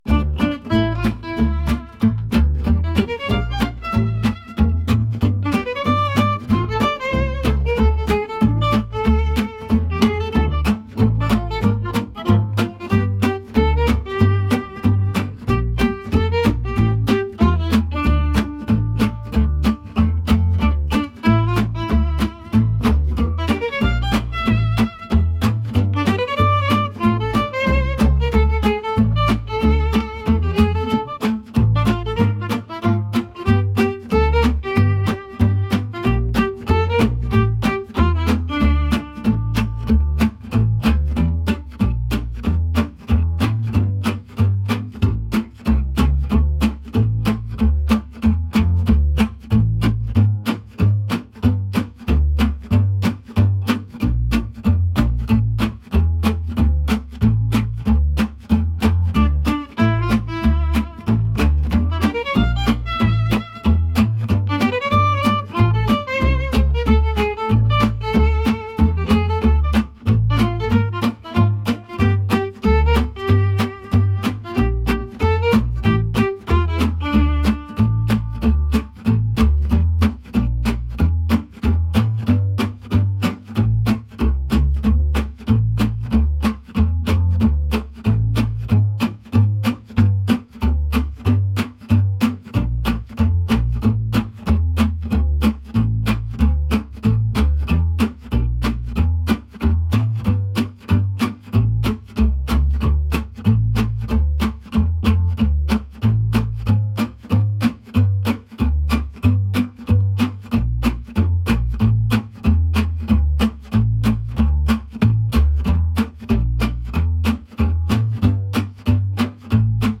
lively | jazz